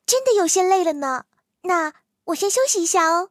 T43中破修理语音.OGG